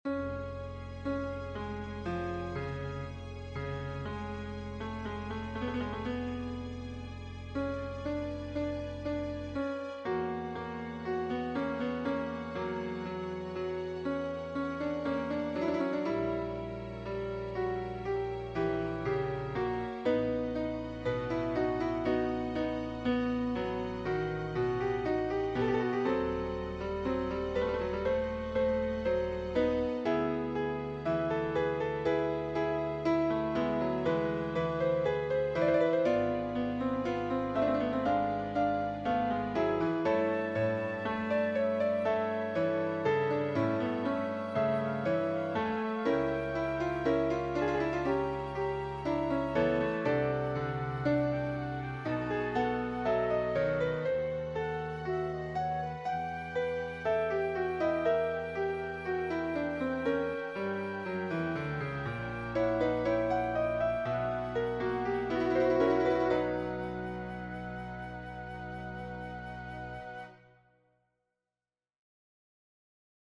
MP3 Dateien von allen Chorstücken nach Register
BWV234-1b Christe_SATB.mp3